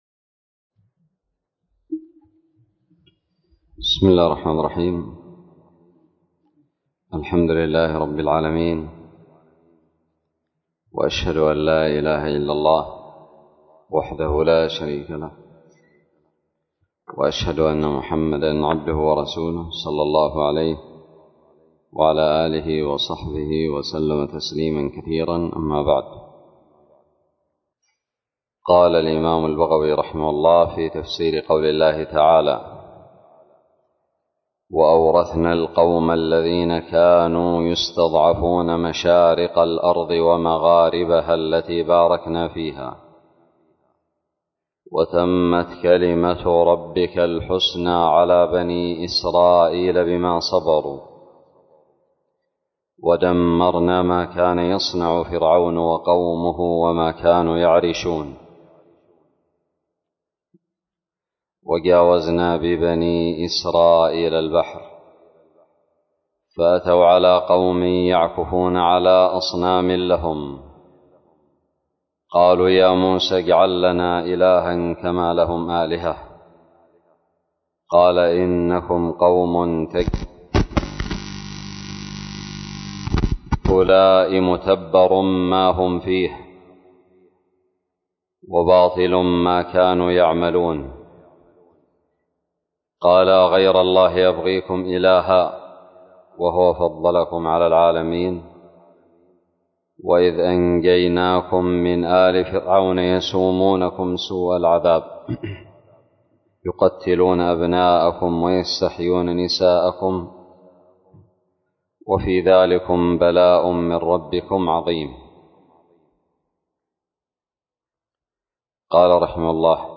الدرس الثامن والعشرون من تفسير سورة الأعراف من تفسير البغوي
ألقيت بدار الحديث السلفية للعلوم الشرعية بالضالع